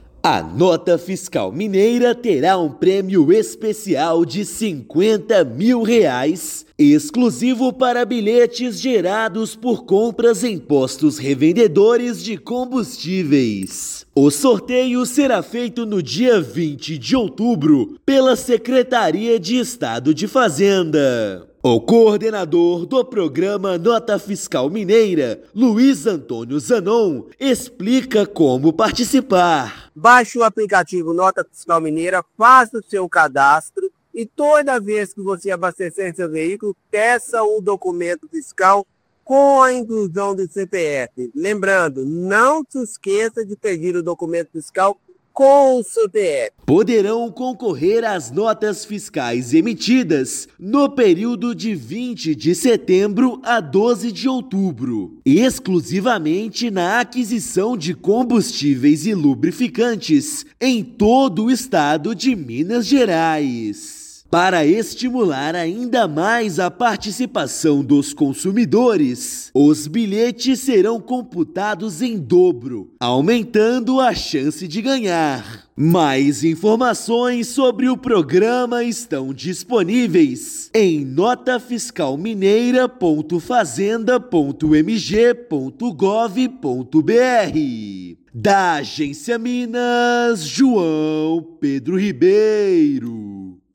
[RÁDIO] Nota Fiscal Mineira terá prêmio especial para compras em postos de combustíveis
Valores de R$ 50 mil para consumidor e R$ 7,5 mil para entidades serão sorteados no dia 20/10. Ouça matéria de rádio.